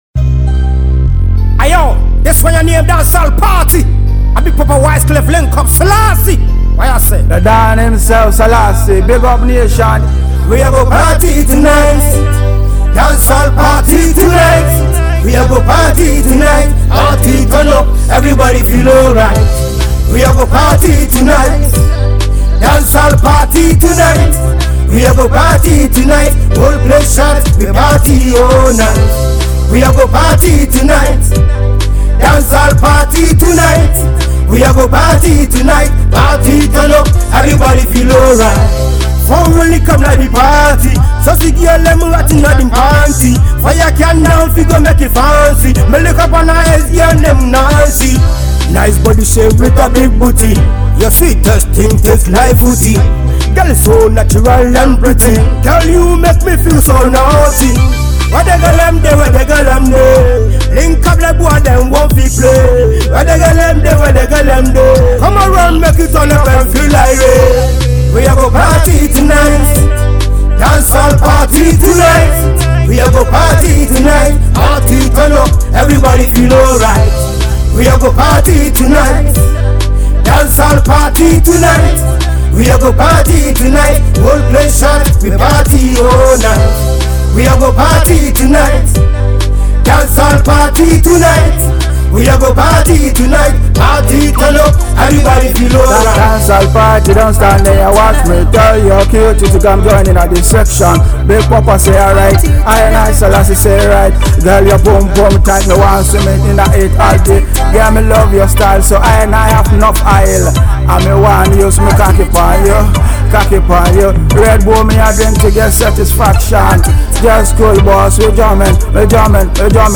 Dancehall Ghana Music